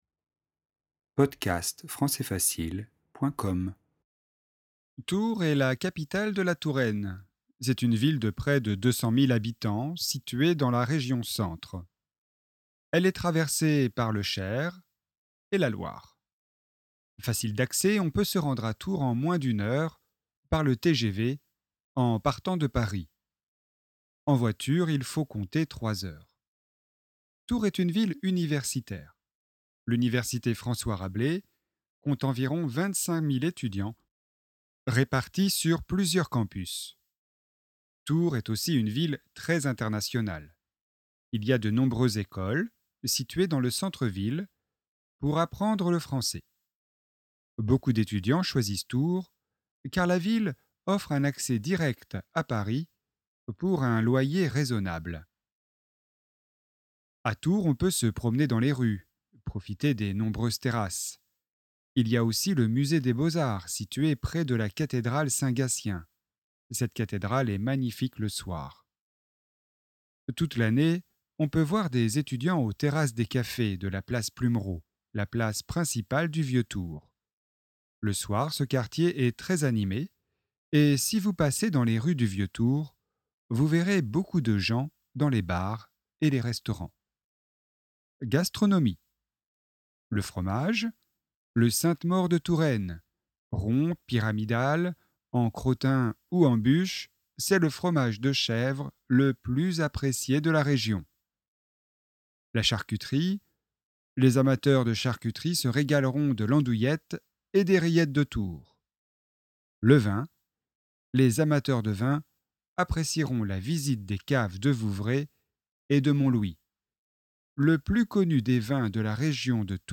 tours-vitesse-normale.mp3